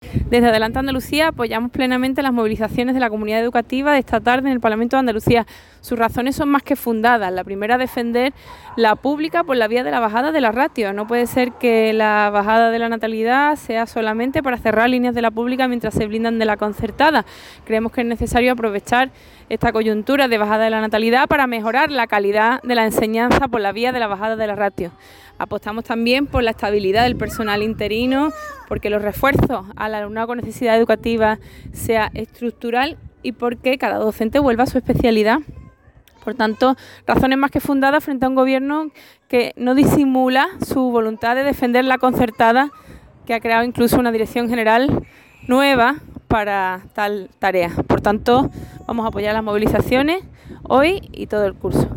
La portavoz de Adelante Andalucía, Teresa Rodríguez, ha defendido las razones “más que fundadas” para comenzar el curso escolar con una concentración educativa.